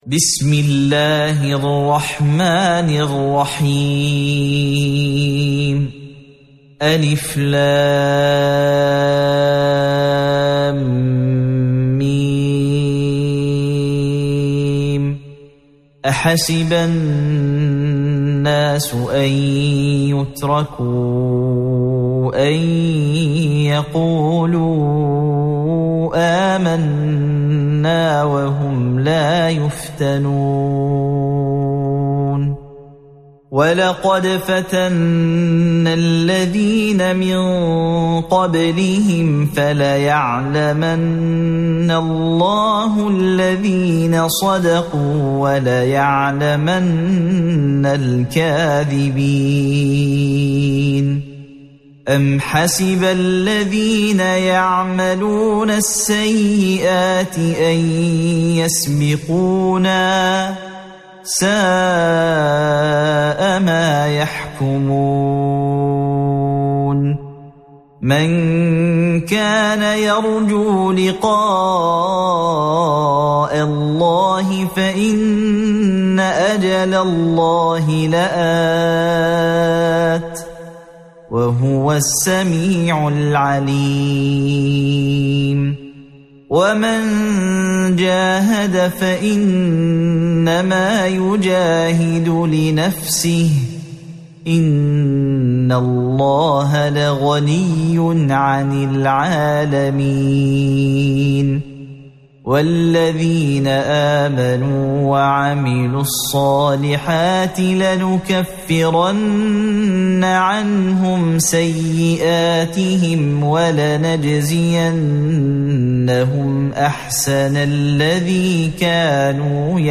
سورة العنكبوت | القارئ